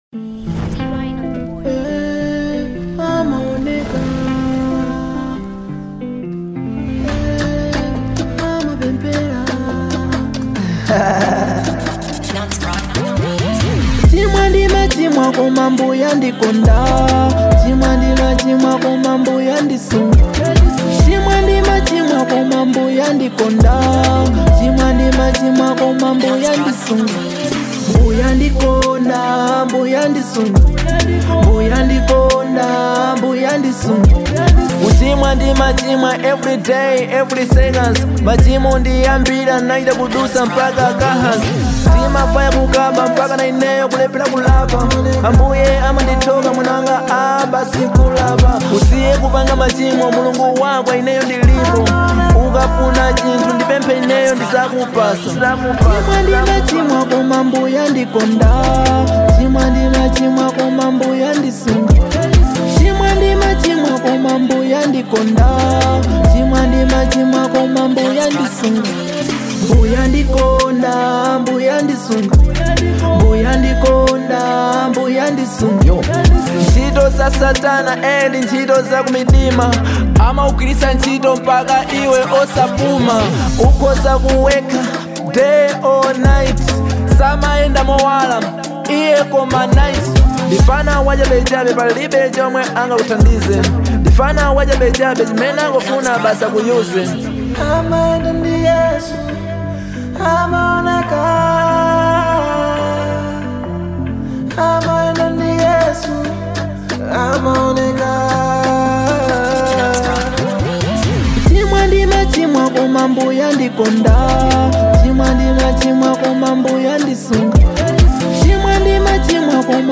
Genre : Gospel
Gospel Hiphop Drill Song